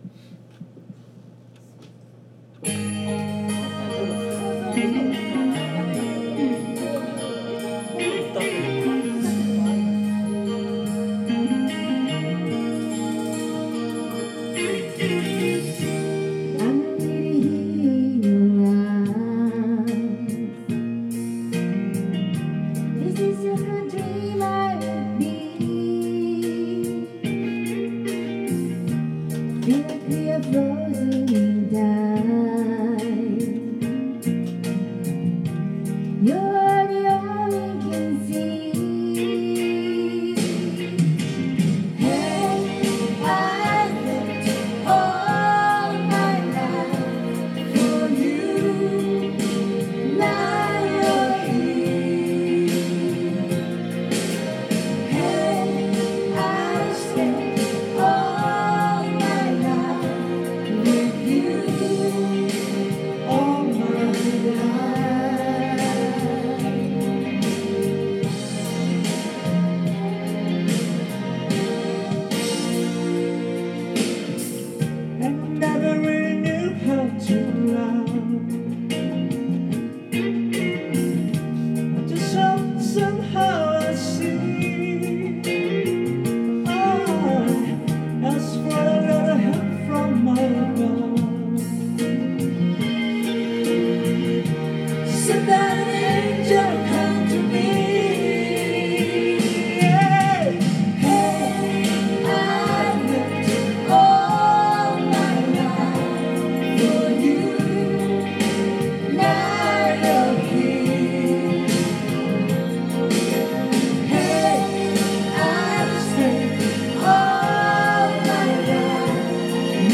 Duet & Chorus Night Vol. 12 TURN TABLE